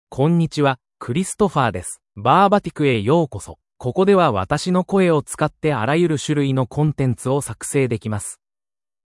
MaleJapanese (Japan)
ChristopherMale Japanese AI voice
Voice sample
Listen to Christopher's male Japanese voice.
Christopher delivers clear pronunciation with authentic Japan Japanese intonation, making your content sound professionally produced.